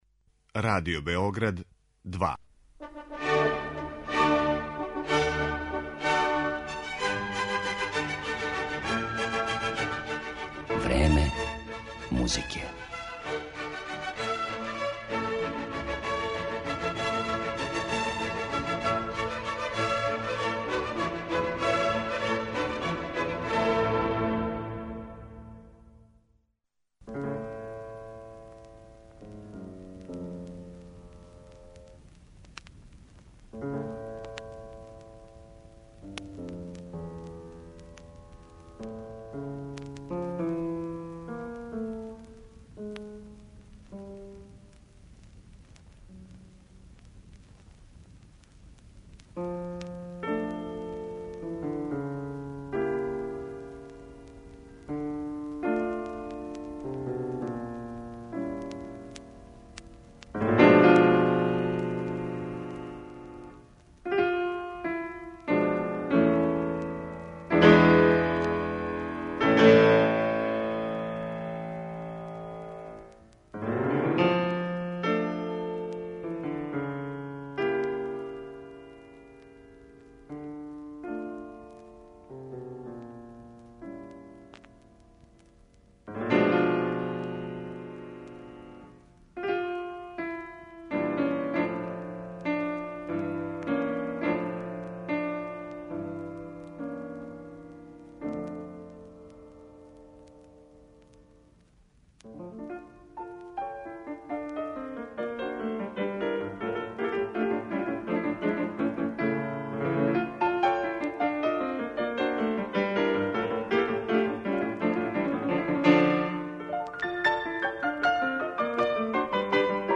Клавирски дуо